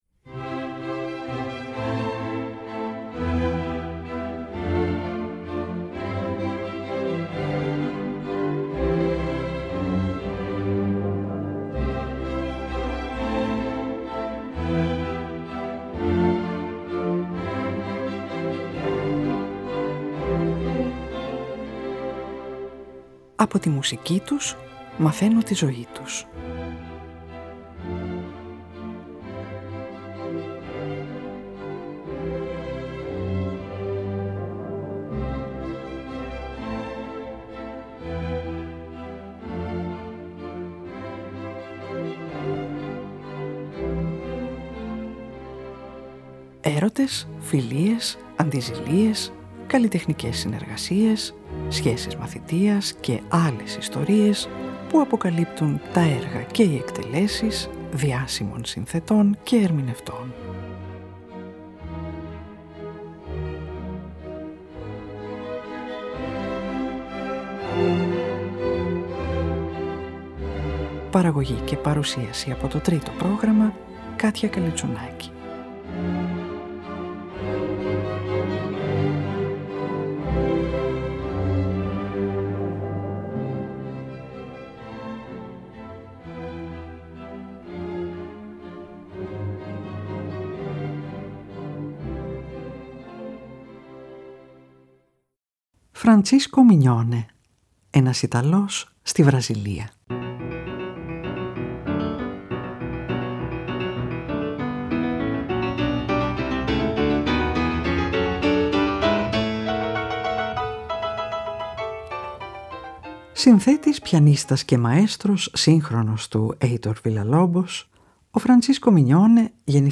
το αφροβραζιλιάνικο χορωδιακό μπαλλέτο του 1933
το 2ο Σεξτέτο για πιάνο και πνευστά του 1969
η 3η από τις 12 Σπουδες για κιθάρα του 1970